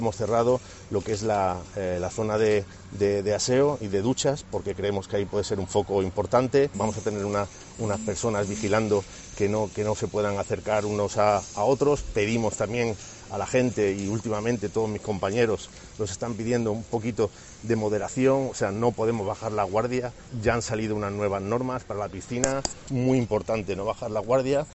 En declaraciones a los periodistas, Torrejimeno ha destacado que desde la última visita a las instalaciones hace dos semanas se han hecho "variaciones muy importantes" con el objetivo de que los visitantes puedan disfrutar del verano, destacando la colación de "540 cuadrados de césped artificial en unas zonas que siempre han tenido barro".